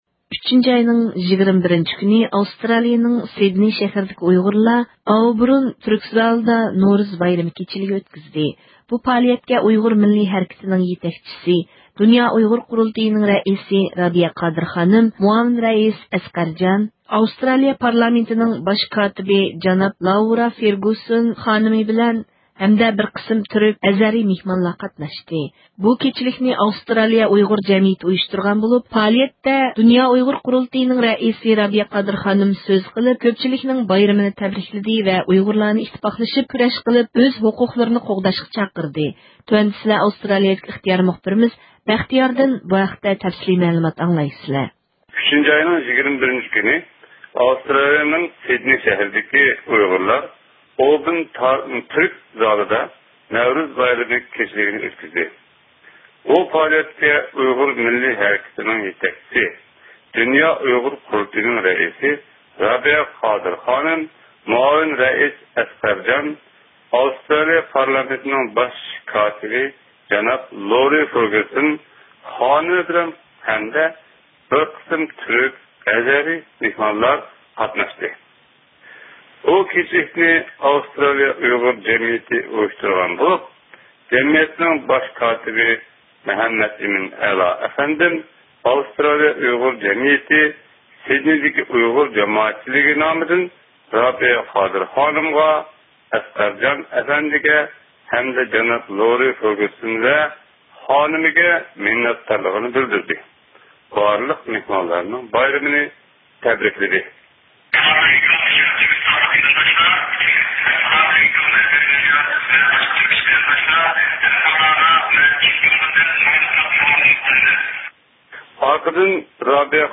سىدنېيدا نورۇز بايرام كېچىلىكى ئۆتكۈزۈلدى – ئۇيغۇر مىللى ھەركىتى